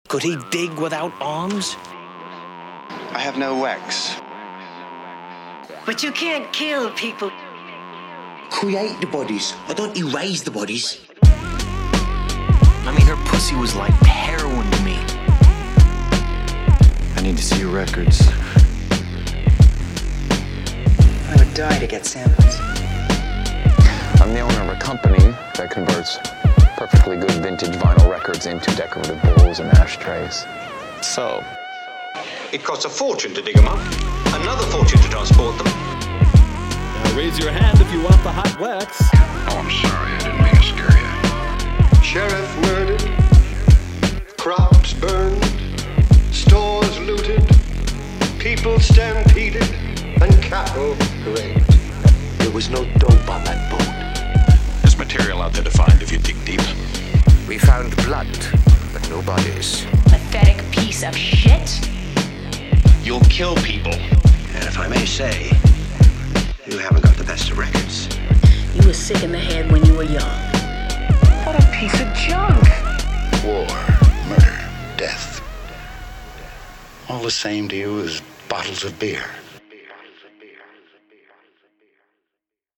He brings you a new pack that contains 80 carefully picked dialogues from vintage movies, radio interviews, retro commercials and obscure documentaries.